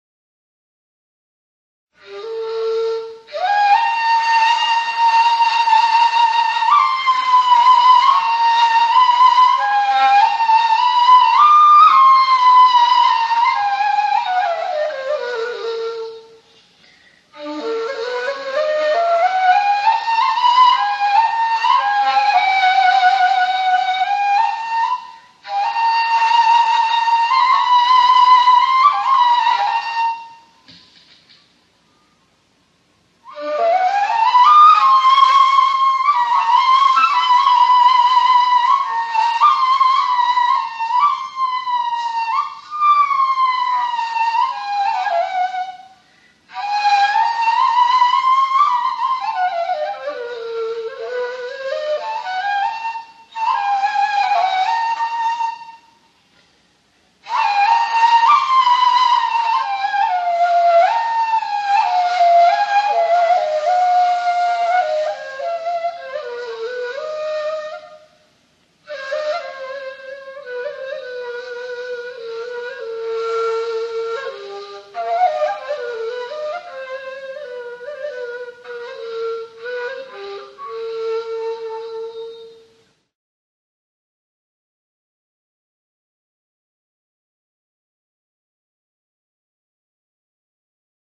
Ney
Sie weist einen sehr markanten Obertonklang auf, und wurde darum auch von jeher in der islamischen Mystik sehr geliebt.
Hier ein weiteres frei improvisiertes Beispiel: